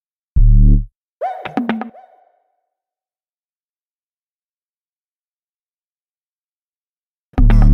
描述：实验性吉他节奏合成器
Tag: 合成器 实验 节拍 吉他